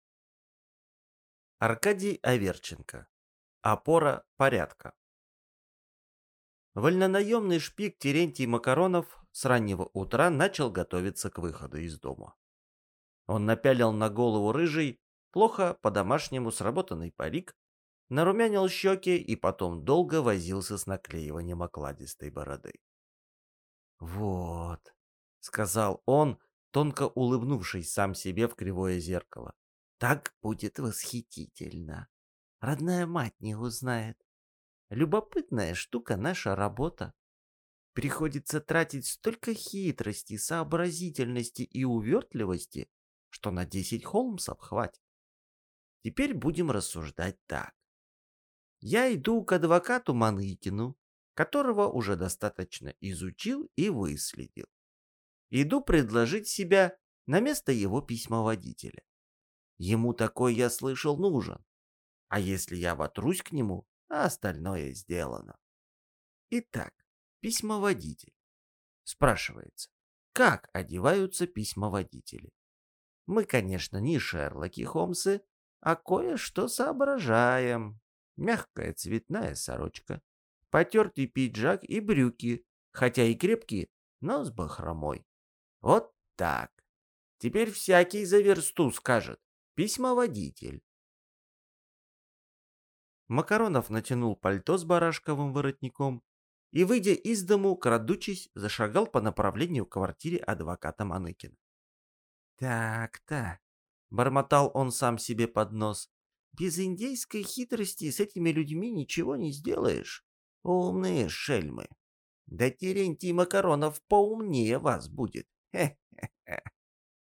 Аудиокнига Опора порядка | Библиотека аудиокниг